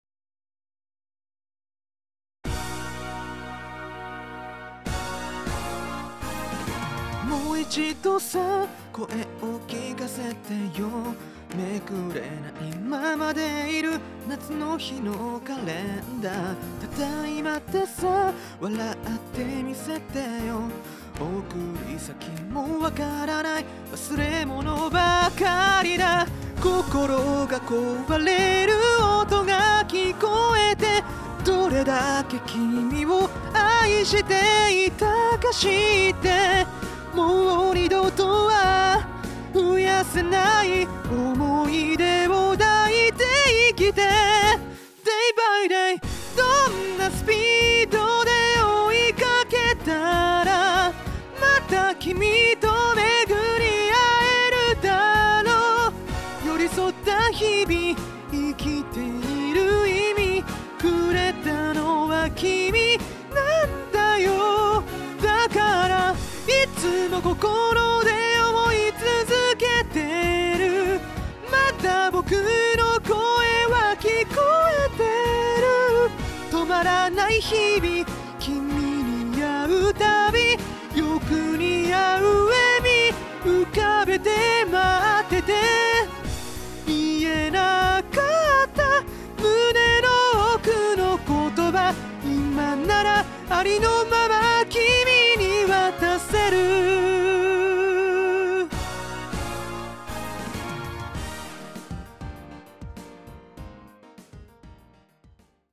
講師の歌唱音源付き記事
音量注意！
※カラオケ音源はこちらからお借りしました。
いや～ず～～っと高いですね・・・ （ ;◉◞౪◟◉)＞